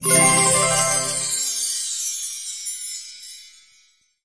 gifteffect.mp3